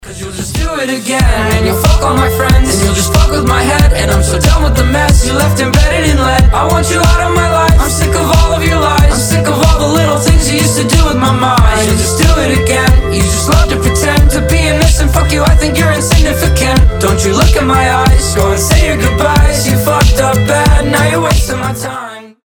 • Качество: 320, Stereo
alternative